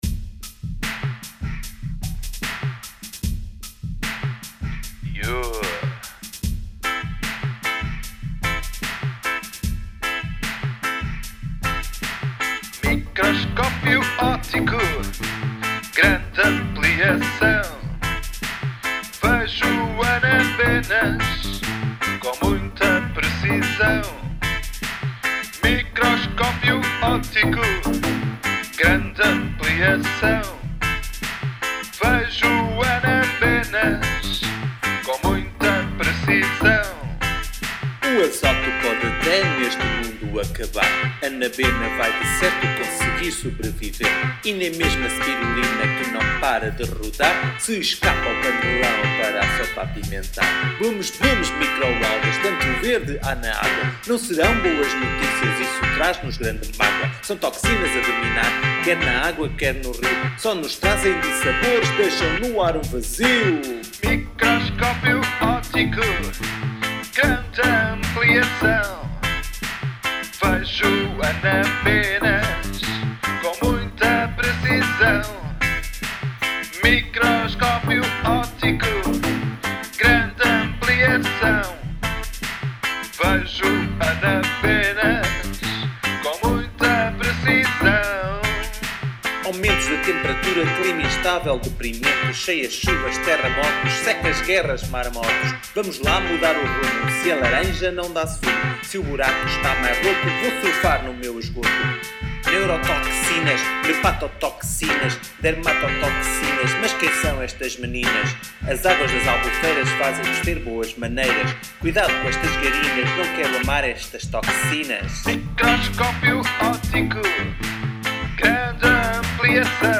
Versão integral: